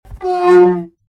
Download Whale sound effect for free.
Whale